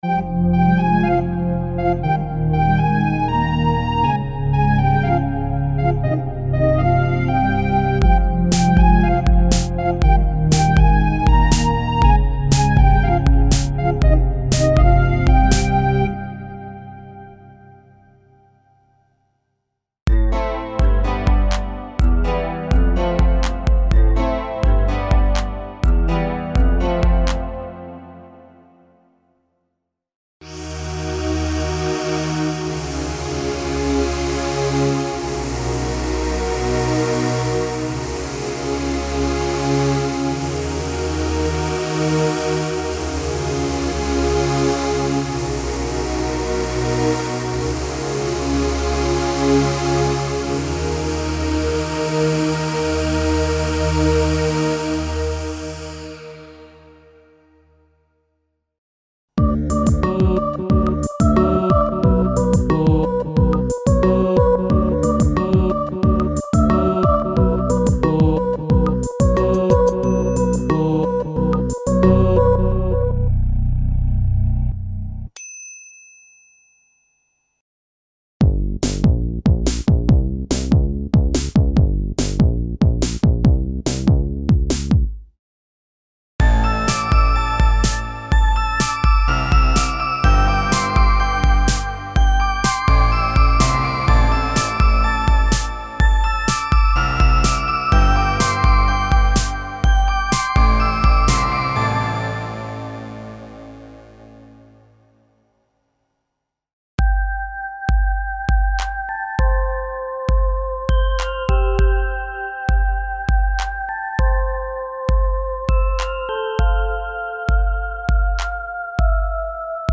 Pop - sound programs for mainstream music styles and electro pop (pianos, guitars, basses, el. strings, pads, synth leads, voices, stacks and hybrid combinations).
Info: All original K:Works sound programs use internal Kurzweil K2661 ROM samples exclusively, there are no external samples used.